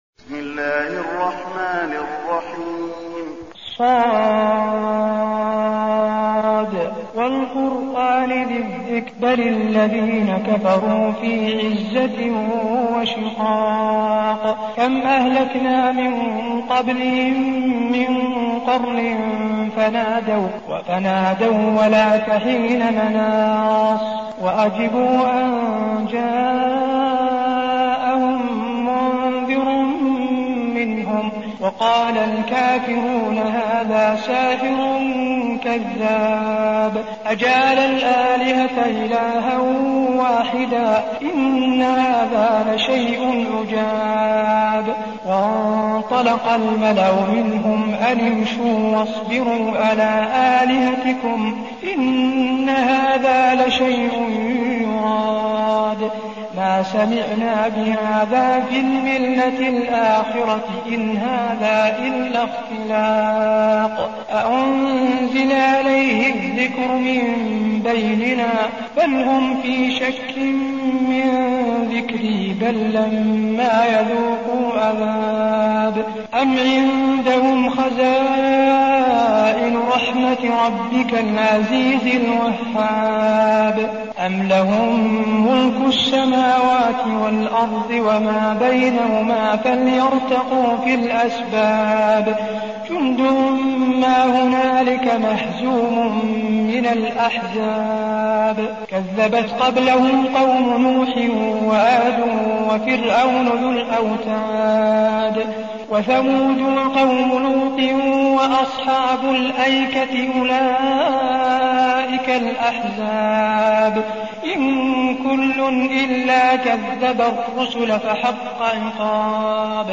المكان: المسجد النبوي ص